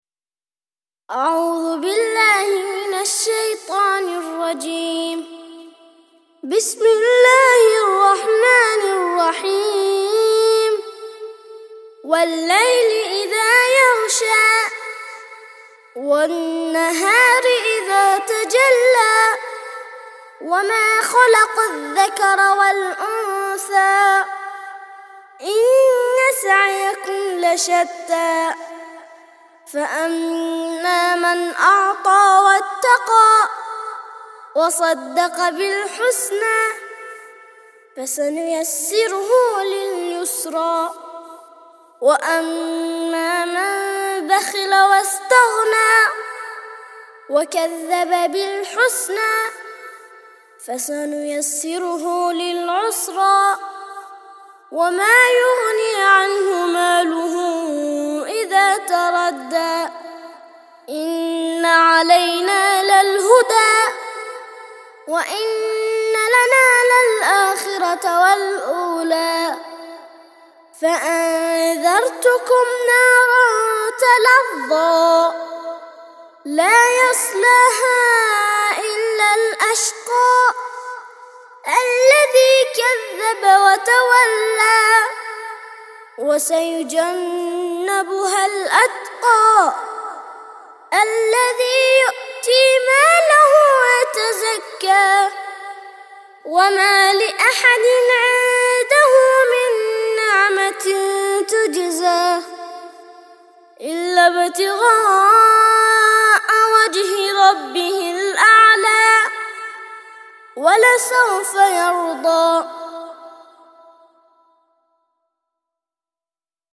92- سورة الليل - ترتيل سورة الليل للأطفال لحفظ الملف في مجلد خاص اضغط بالزر الأيمن هنا ثم اختر (حفظ الهدف باسم - Save Target As) واختر المكان المناسب